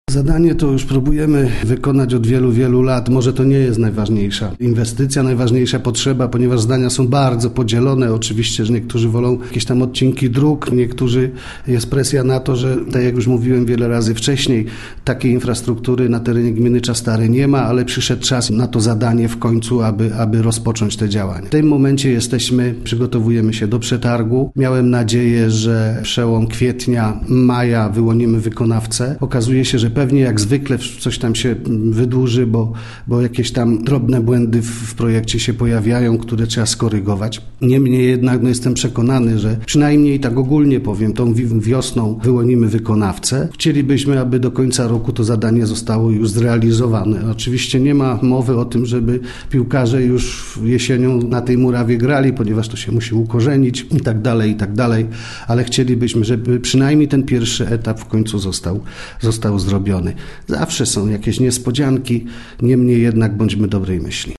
– mówił wójt, Dariusz Rejman.